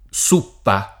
suppa [ S2 ppa ] → zuppa